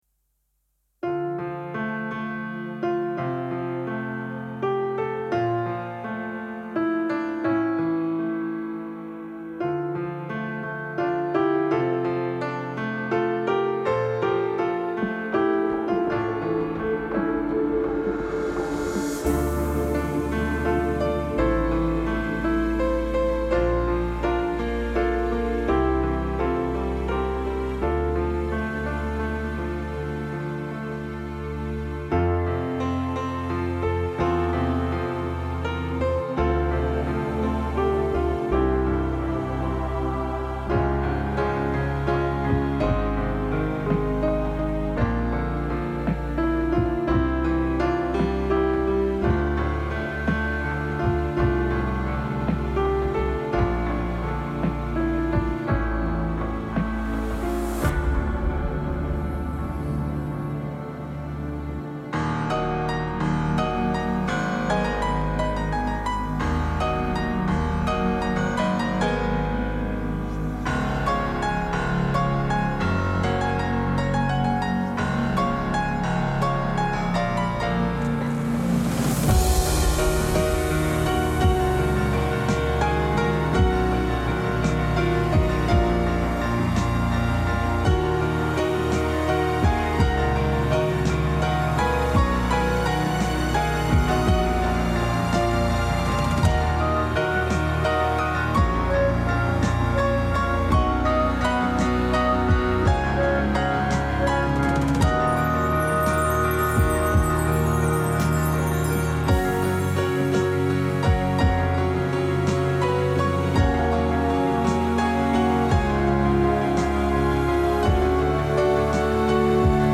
Inno all' amicizia - Freundschaftshymne Gilching-Cecina